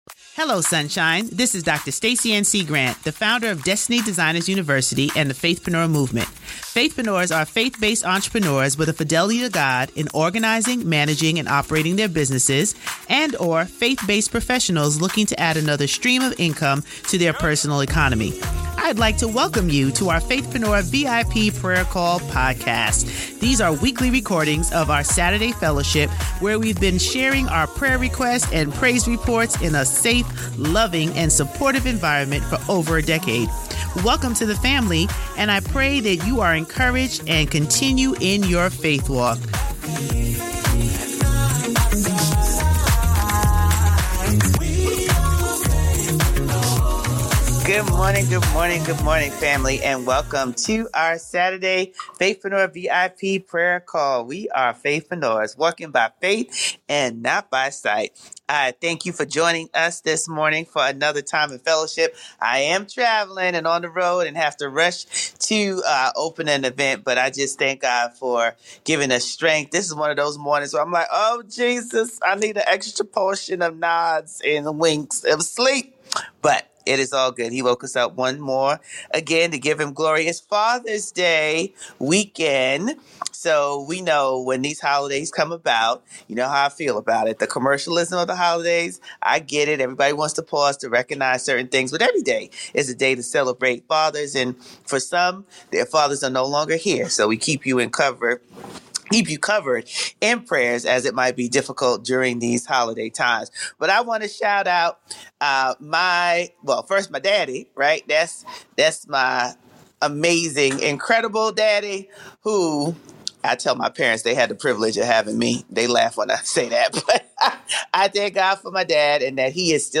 1 How do I build resilience when things do not go as planned? 1:21:43 Play Pause 11h ago 1:21:43 Play Pause Play later Play later Lists Like Liked 1:21:43 Teaching: How do I build resilience when things do not go as planned? Scriptures: James 4:13-15, Isaiah 41:10, Romans 5:3-5, Lamentations 3:21-23, Philippians 3:13-14 Ministry Announcements & Events Join us weekly and stay plugged in: Sunday Live Worship – Every Sunday @ 9AM EST Share the link and invite someone to worship with us!